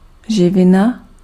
Ääntäminen
Ääntäminen Tuntematon aksentti: IPA: /ʒɪvɪna/ Haettu sana löytyi näillä lähdekielillä: tšekki Käännös Substantiivit 1. nutrient Suku: f .